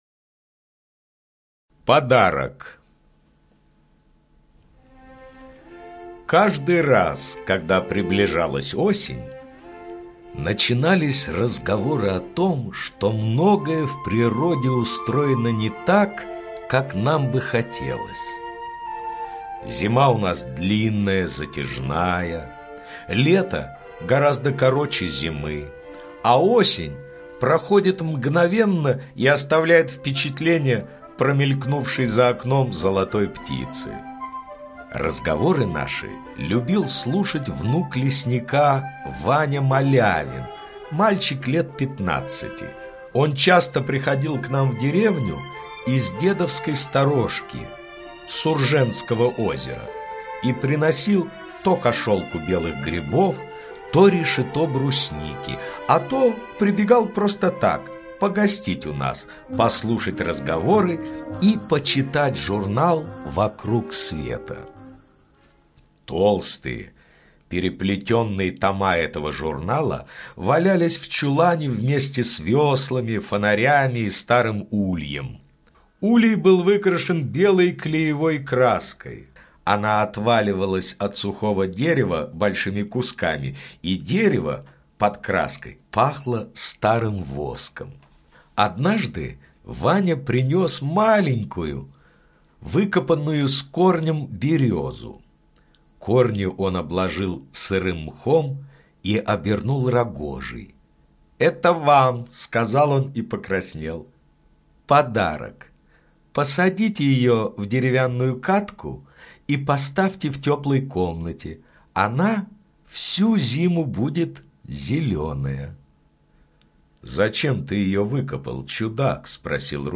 Подарок - аудио рассказ Паустовского К. История о том, как автору в подарок внук лесника принес маленькую березу, выкопанную в лесу.